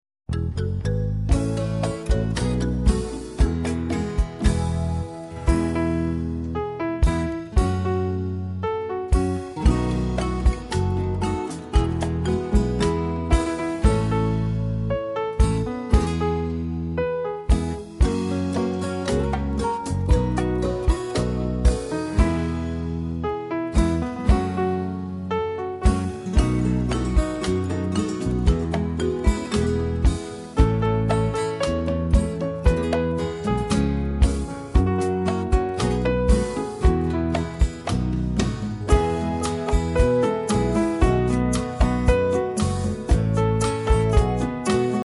A Movement Song for Teaching Colors